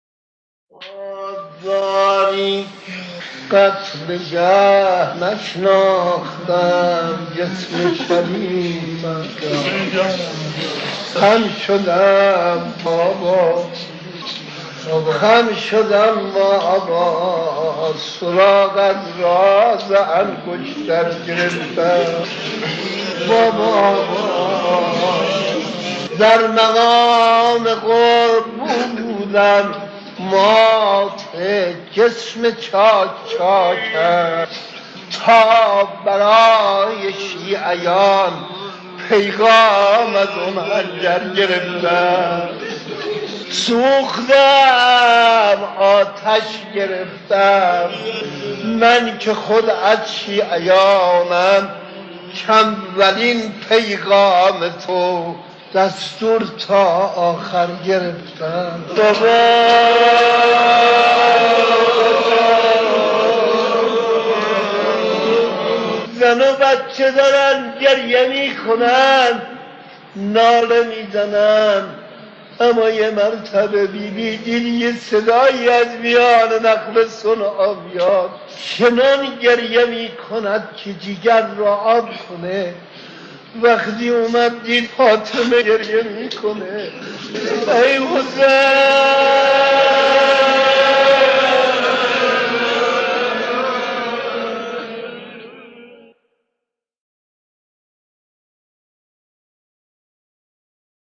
در پرده عشاق، صدای مداحان و مرثیه‌خوانان گذشته تهران قدیم را خواهید شنید که صدا و نفس‌شان شایسته ارتباط دادن مُحب و مَحبوب بوده است.
مصیبت‌خوانی در روز اربعین؛ مرور خاطره عصر عاشورا از زبان حضرت سکینه (س) دختر نوجوان امام حسین (ع)